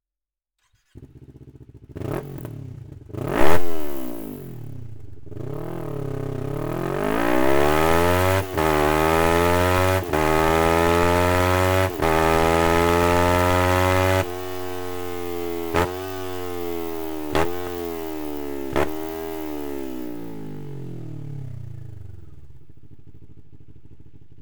Akrapovic Austauschkrümmer Edelstahl, ohne Straßenzulassung; für Kawasaki
Geräusch 98.9 dB bei 5000 U/min (+12.3 dB)
Sound Akrapovic Komplettanlage